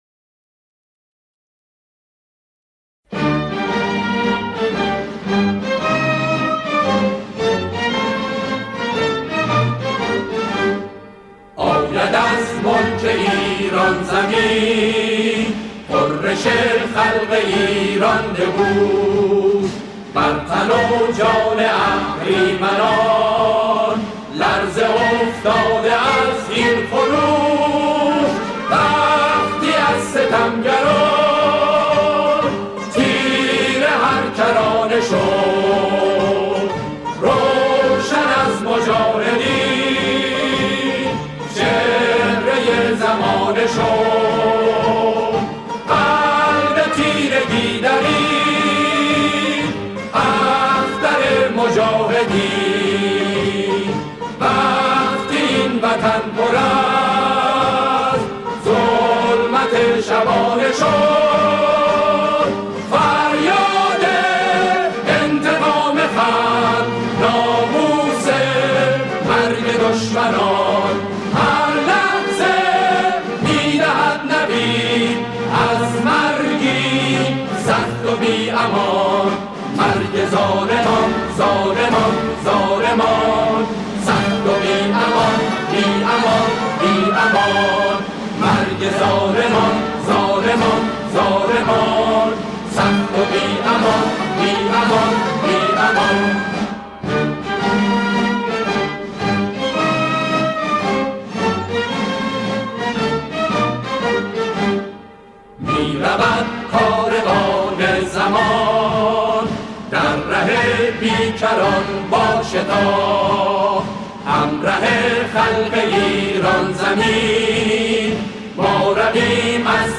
گروهی از همخوانان